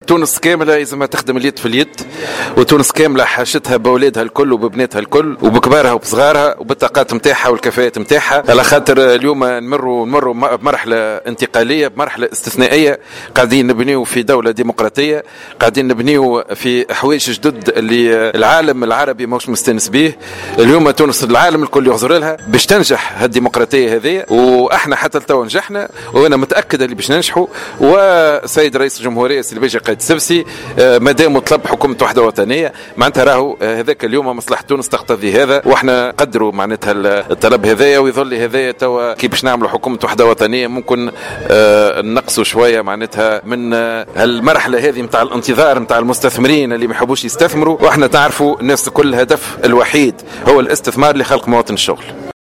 قال وزير المالية سليم شاكر في تصريح لمراسل "الجوهرة أف أم" على هامش الزيارة التي أداها أمس الخميس إلى ولاية صفاقس إن مصلحة تونس تقتضي الدعوة لتشكيل حكومة وحدة وطنية.